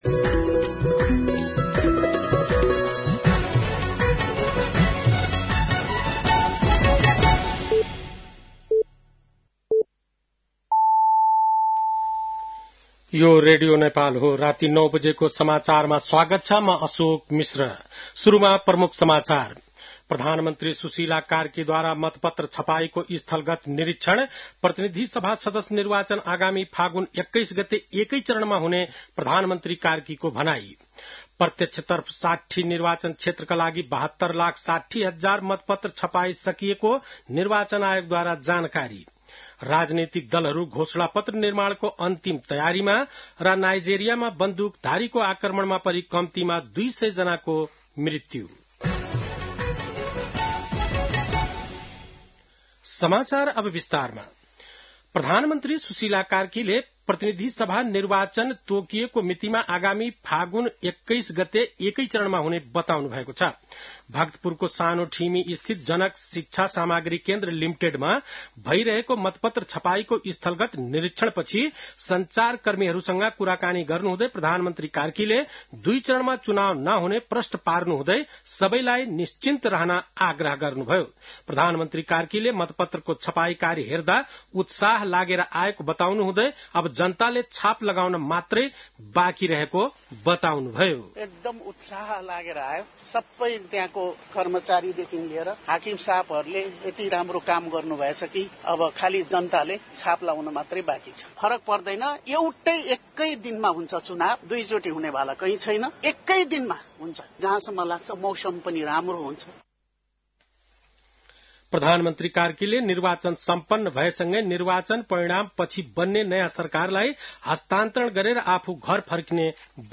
बेलुकी ९ बजेको नेपाली समाचार : २२ माघ , २०८२
9-PM-Nepali-NEWS-10-22.mp3